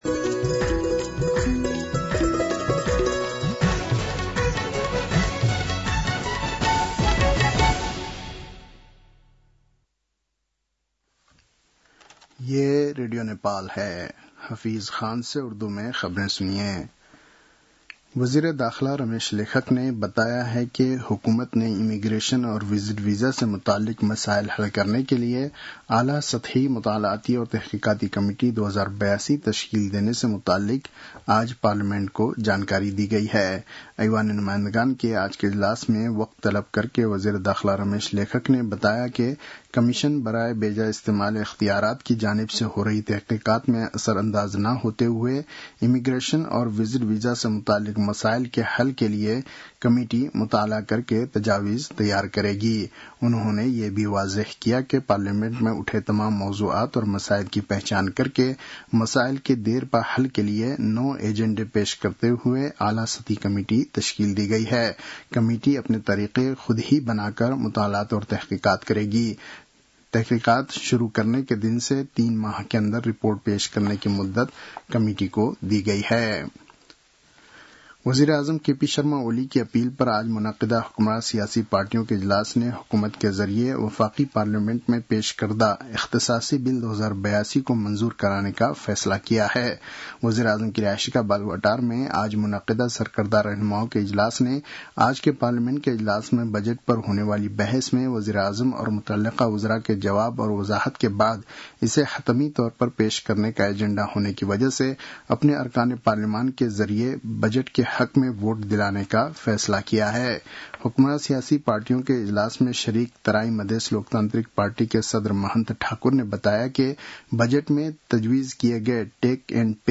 An online outlet of Nepal's national radio broadcaster
उर्दु भाषामा समाचार : १० असार , २०८२